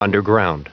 Prononciation du mot underground en anglais (fichier audio)
Prononciation du mot : underground